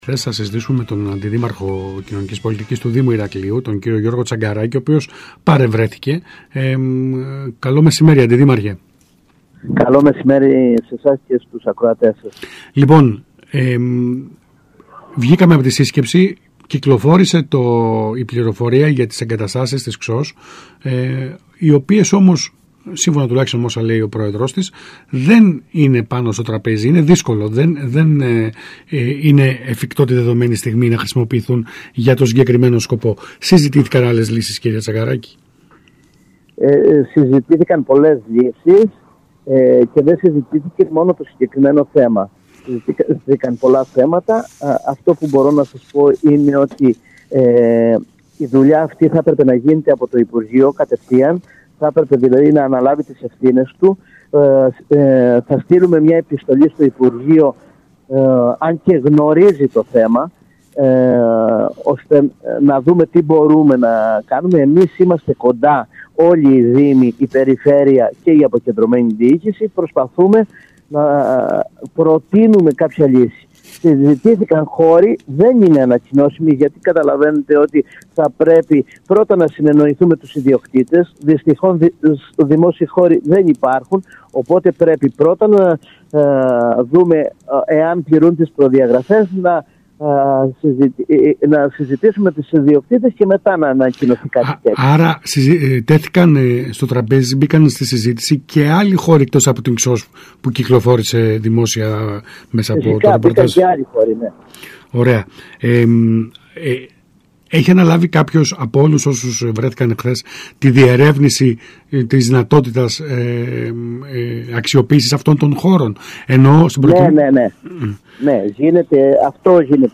Ακούστε εδώ όσα είπε στον ΣΚΑΙ Κρήτης ο Αντιδήμαρχος Κοινωνικής Πολιτικής Γιώργος Τσαγκαράκης: